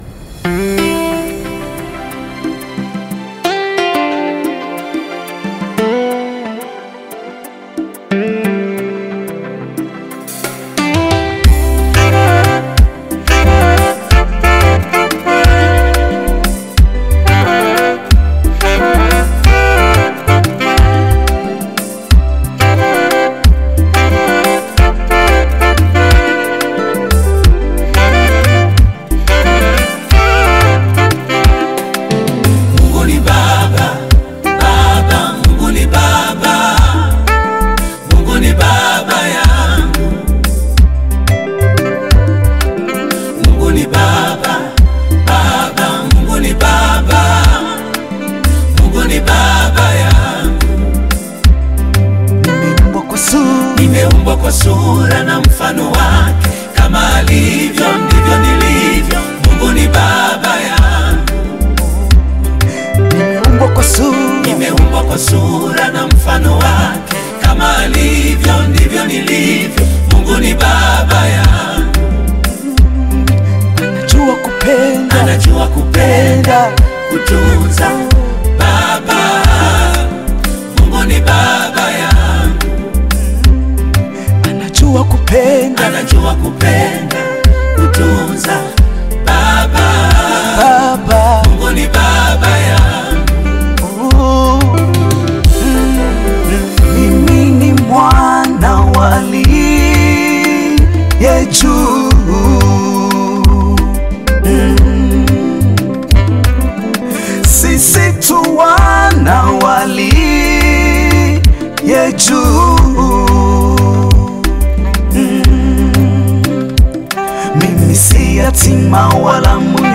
Gospel music track
Tanzanian Gospel